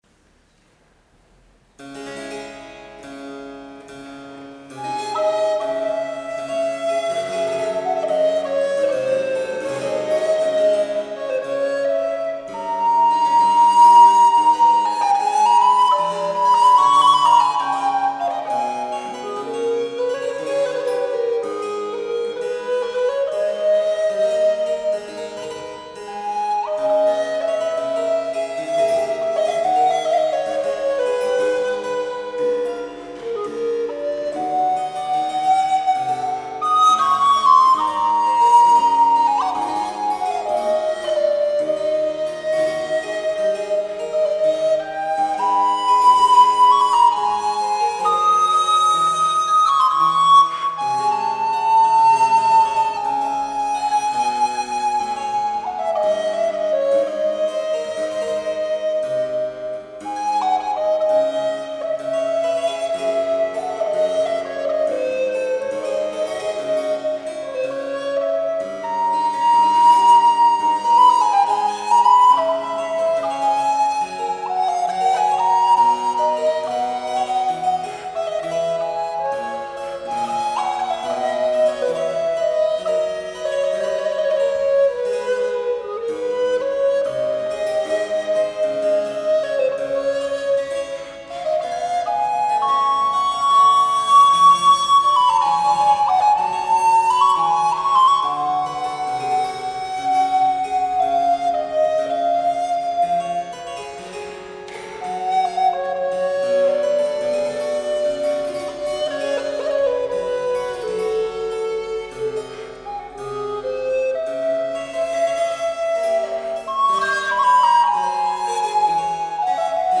ascolto (flauto dolce e cembalo)
flauto dolce- clavicembalo.mp3